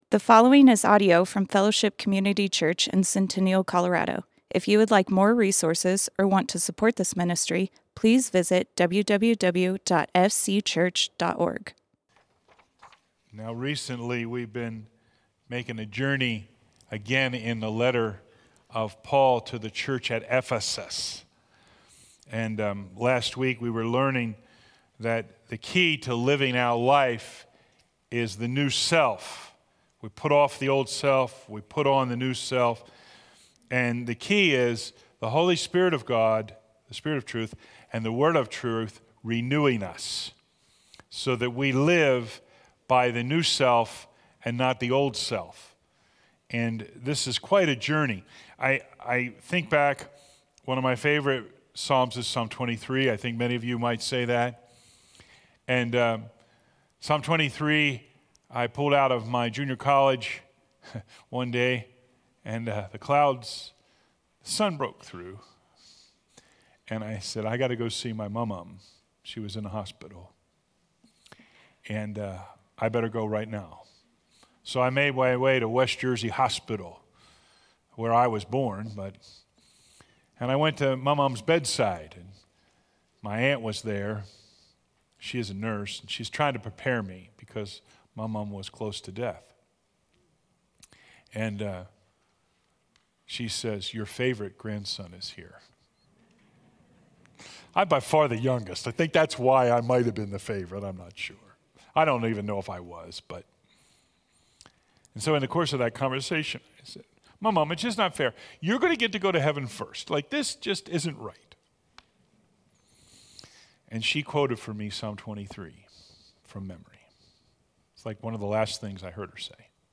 Fellowship Community Church - Sermons The Devil's Foothold Play Episode Pause Episode Mute/Unmute Episode Rewind 10 Seconds 1x Fast Forward 30 seconds 00:00 / Subscribe Share RSS Feed Share Link Embed